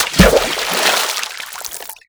splash.ogg
I also purchased the uncompressed water splash sound.